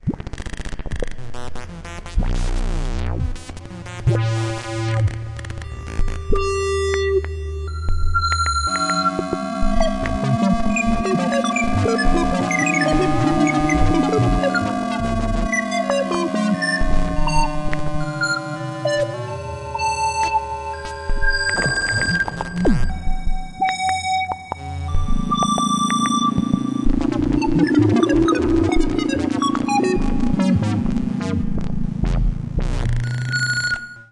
标签： 电子 合成器 实验性 舞蹈
声道立体声